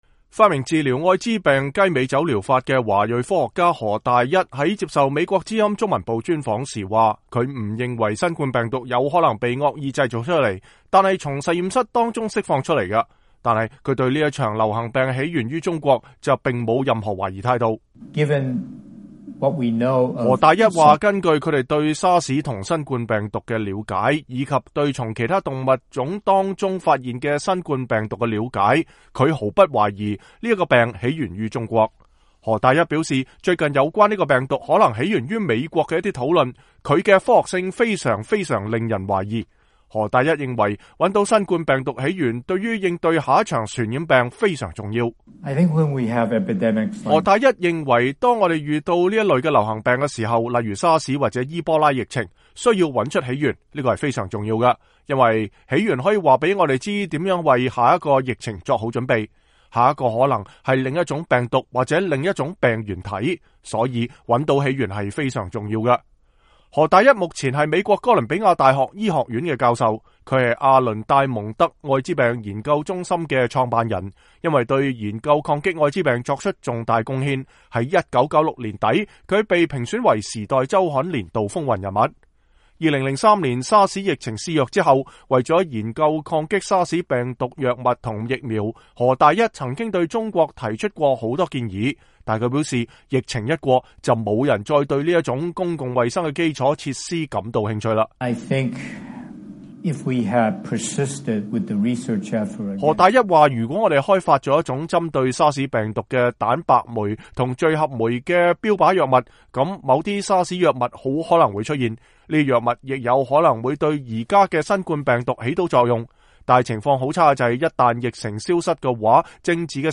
他在接受美國之音專訪時說，人類在20年時間裡遭遇3次冠狀病毒來襲（SARS、MERS、COVID-19）。沙士（SARS）後的教訓是，人類不能再犯同樣錯誤，必須找到一個科學的解決方案。